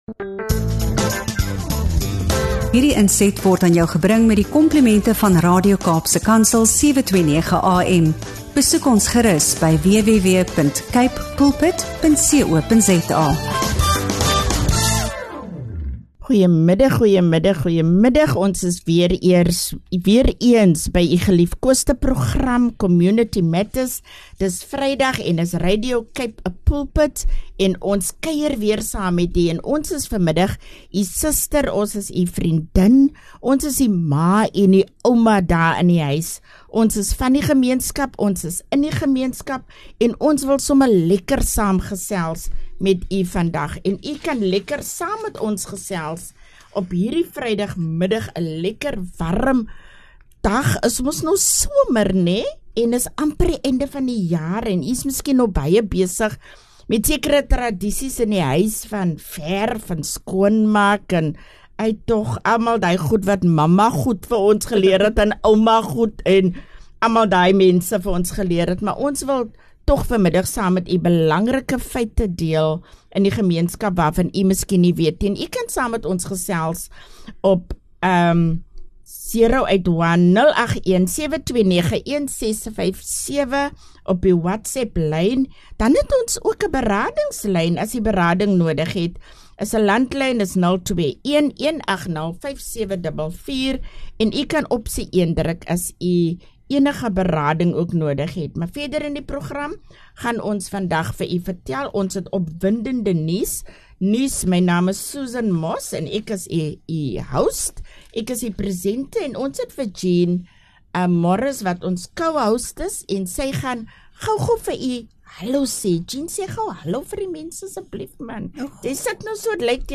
In hierdie week se episode van Community Matters skyn ons die kollig op twee kragtige vrouestemme wat werklike verandering in ons gemeenskappe dryf.
Hierdie diepgaande gesprek fokus op geslagsgebaseerde geweld, gemeenskapsherstel, bemagtiging van vroue, en die krag van kollektiewe aksie.